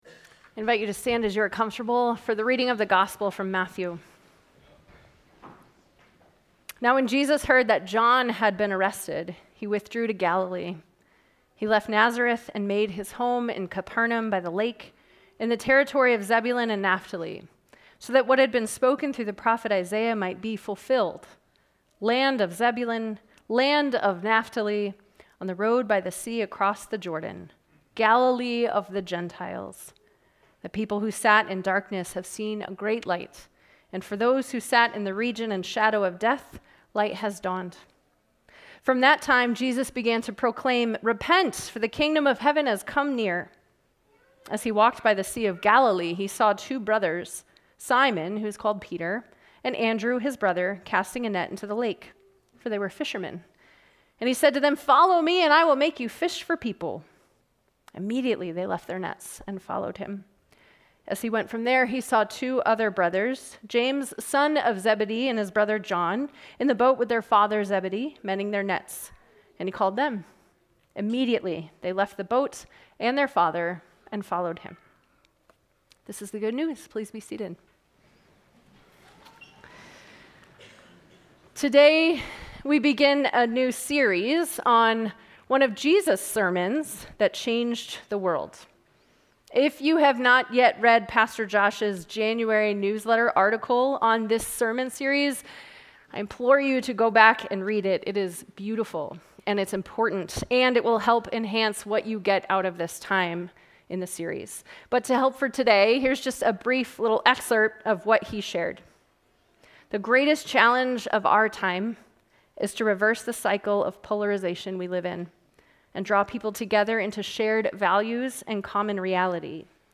Sermons | Good Shepherd Lutheran Church